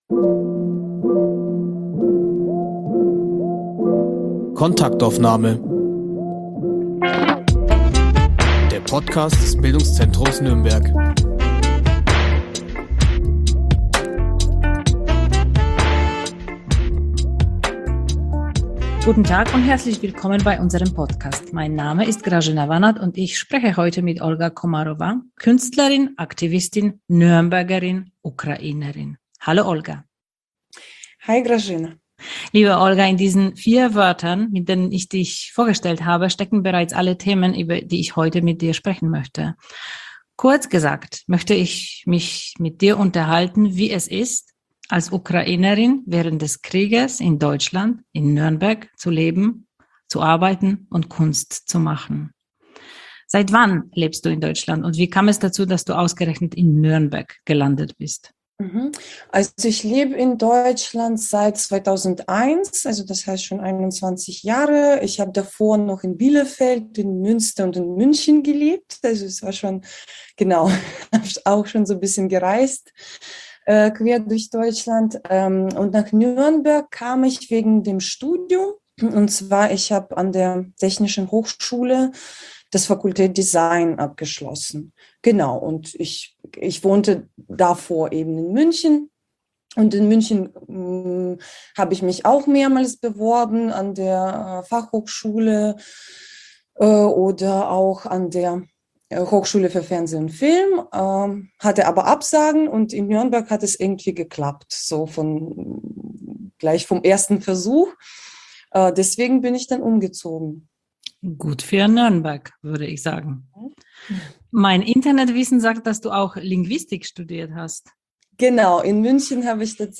Live Aufnahme der 100. Folge von unserem Podcast